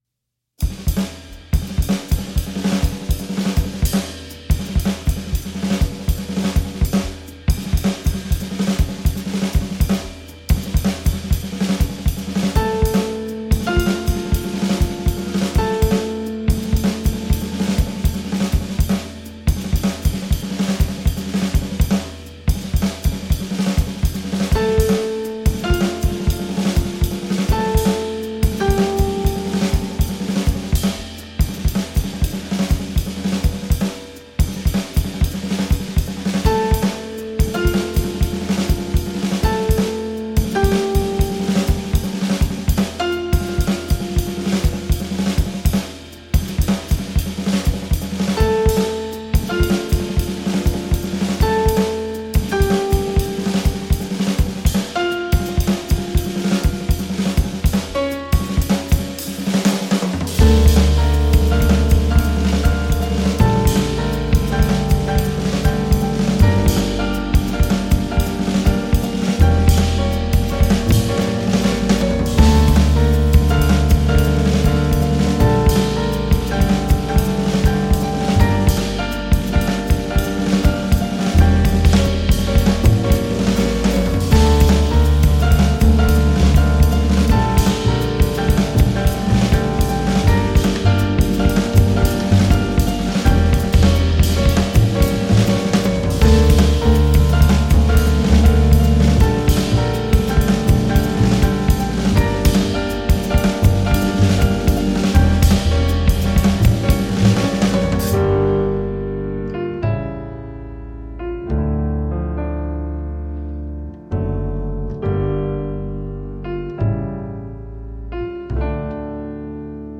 batterie
piano
flûte traversière
contrebasse